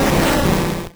Cri de Foretress dans Pokémon Or et Argent.